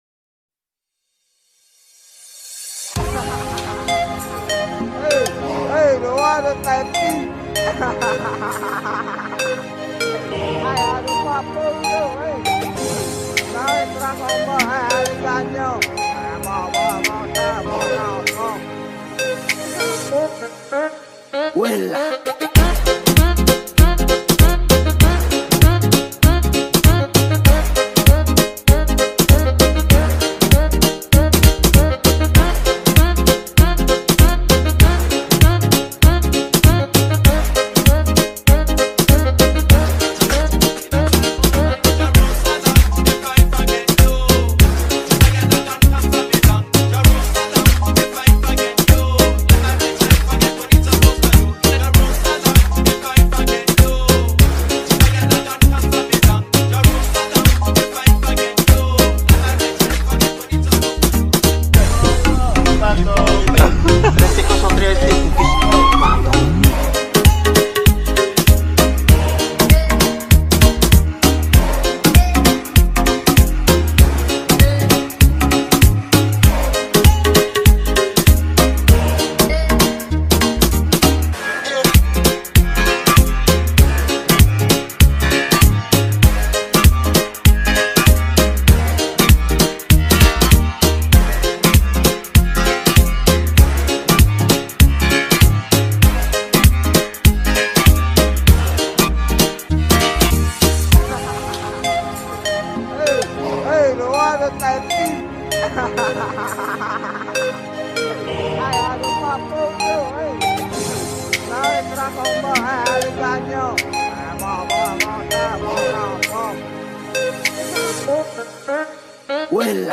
это зажигательная композиция в жанре латин-поп